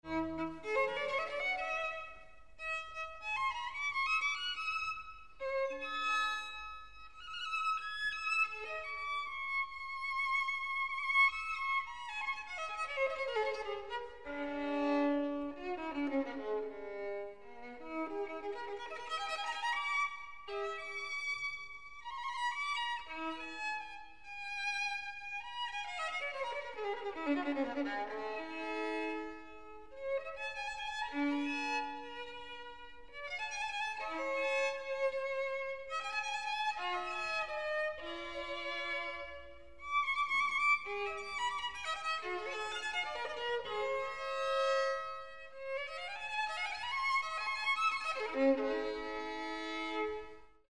Mozart’s fortepiano is neither signed nor dated, but can be attributed to the piano maker Anton Walter (1752–1826) and was probably built around 1782. Mozart acquired it before 1785 as a concert instrument and played it at his public performances in Vienna.